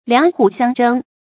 讀音讀法：
兩虎相爭的讀法